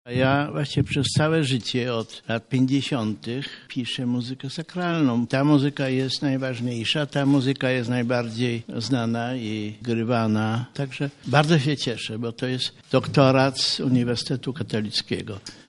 Znany profesor wyróżniony na KUL-u. Krzysztof Penderecki podczas Kongresu Kultury Chrześcijańskiej otrzymał tytuł doktora honoris causa Katolickiego Uniwersytetu Lubelskiego.
O swojej twórczości mówi sam uhonorowany Krzysztof Penderecki.